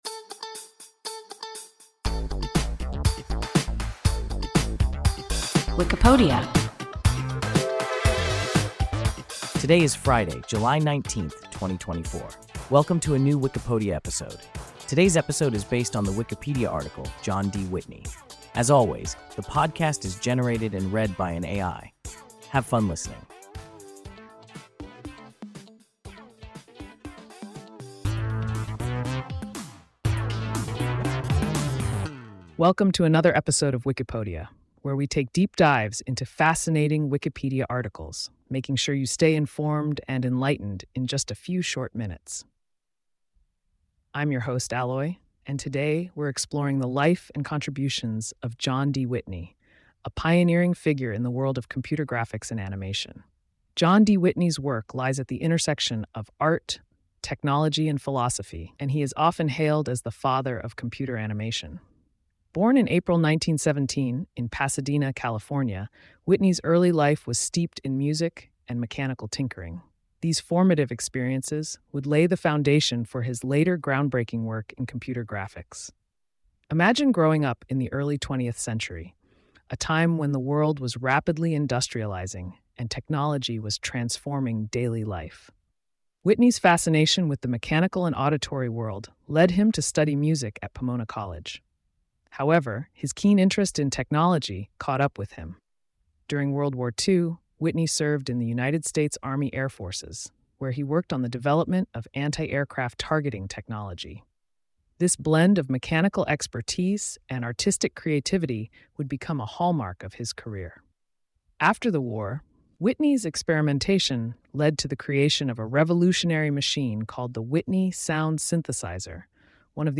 John D. Whitney – WIKIPODIA – ein KI Podcast